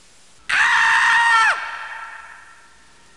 Scream Sound Effect
Download a high-quality scream sound effect.
scream-4.mp3